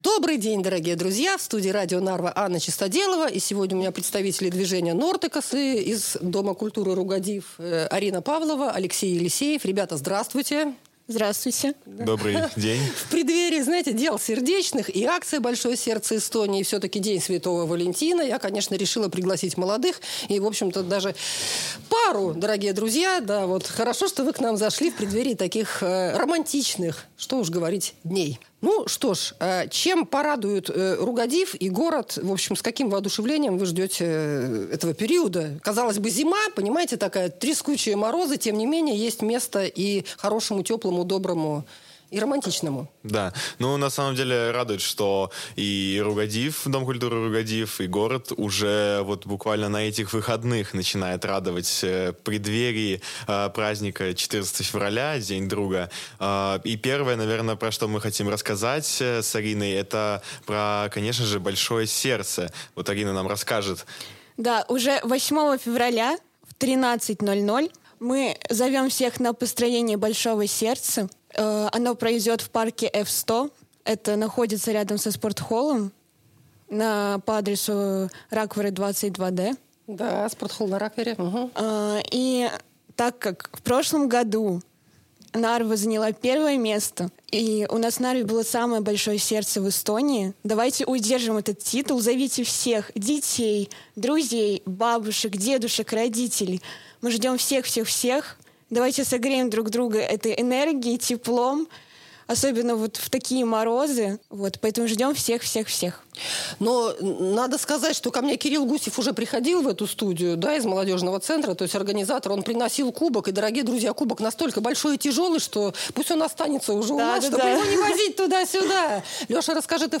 О том, как с пользой и интересом поучаствовать в мероприятиях города и Дома культуры, которые стартуют уже 8 февраля, они рассказали в интервью на Radio Narva.